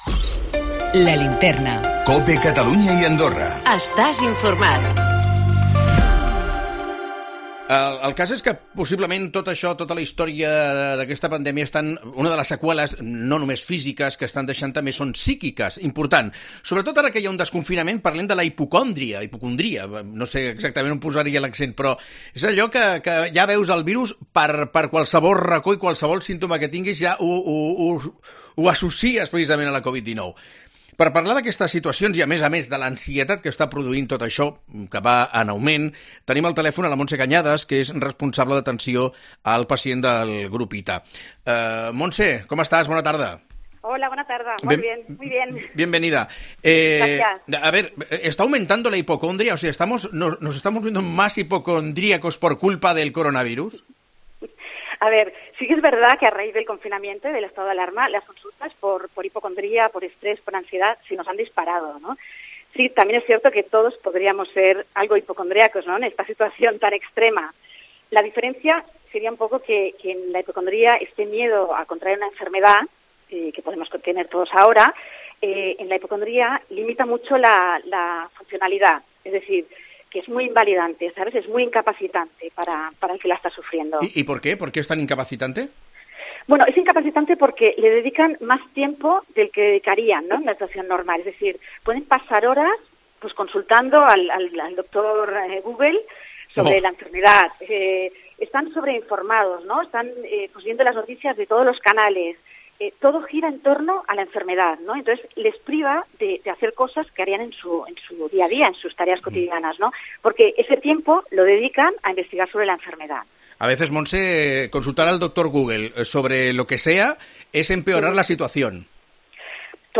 Las consultas por estres y ansiedad se han disparado estos últimos meses, quizás, sí que es verdad, que todos somos algo más hipocondríacos, todos tenemos algo más de miedo, por eso hoy nos ha visitado